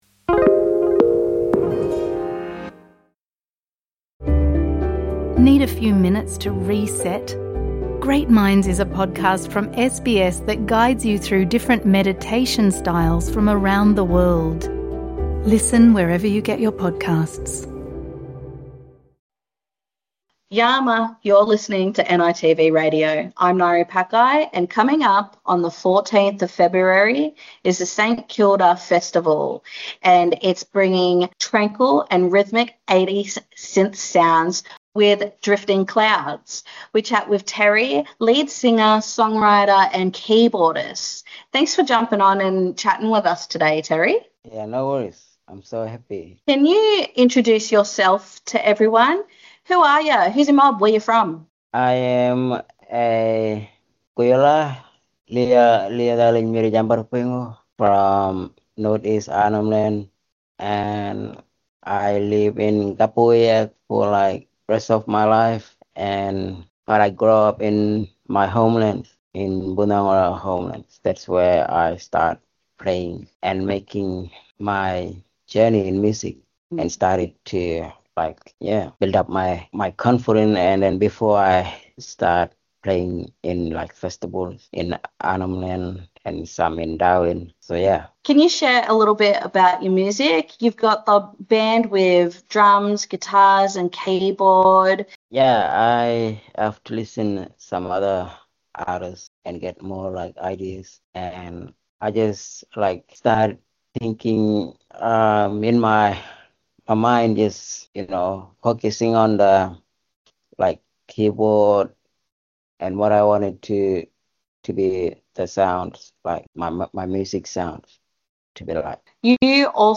NITV Radio have a conversation